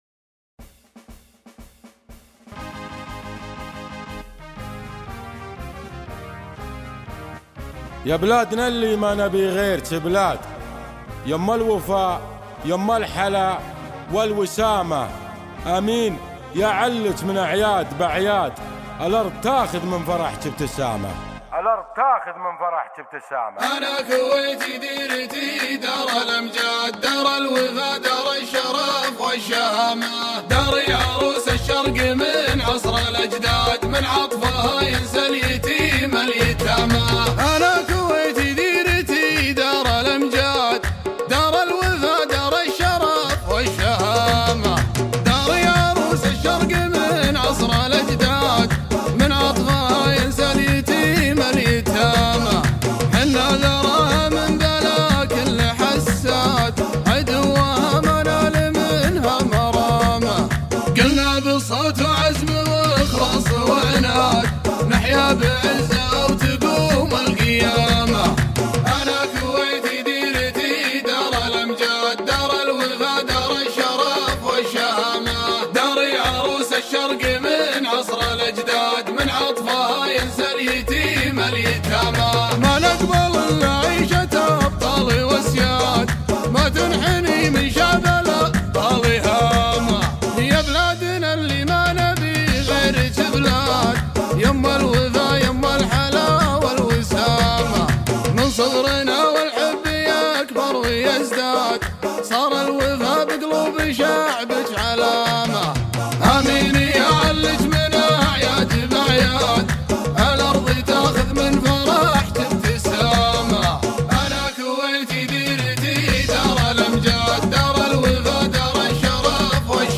شيلة (وطنية)